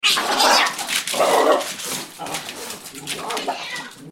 На этой странице собраны звуки драки между кошкой и собакой – от яростного шипения и лая до забавного визга и рычания.
Звук драки собаки и кошки